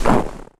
STEPS Snow, Run 27.wav